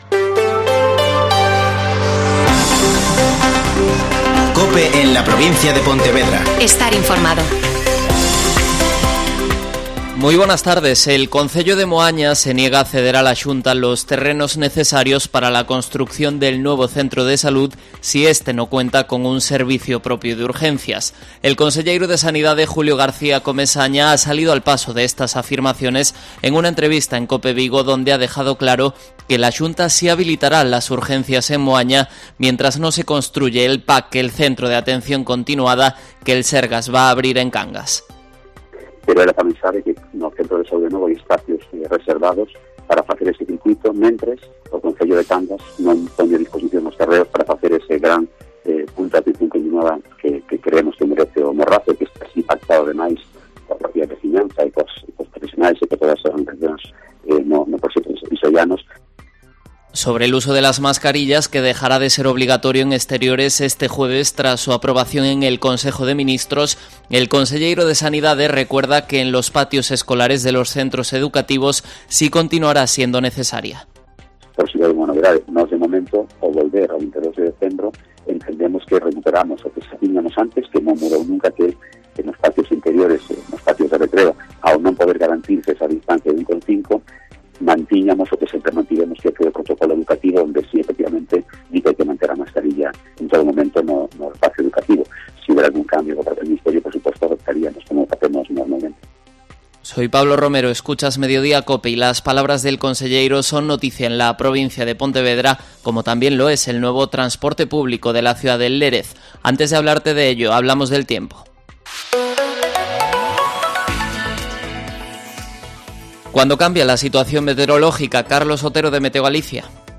Mediodía COPE en la Provincia de Pontevedra (Informativo 14:20h.)